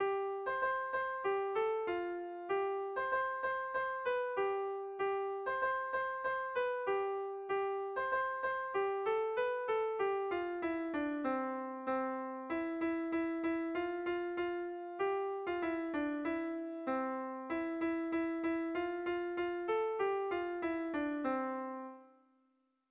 Bertso melodies - View details   To know more about this section
Dantzakoa
A1A2B1B2